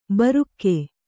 ♪ barukke